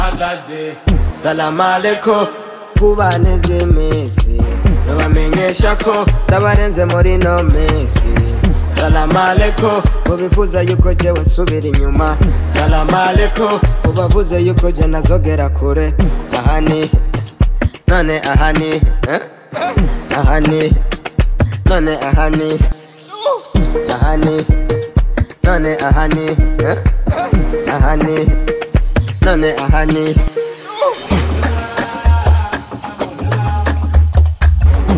Жанр: Поп музыка
Afro-Beat, African, Afro-Pop